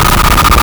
Player_Glitch [3].wav